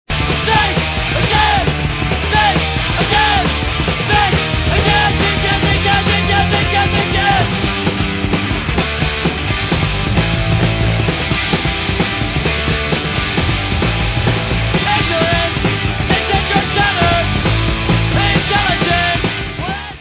sledovat novinky v oddělení Rock/Hardcore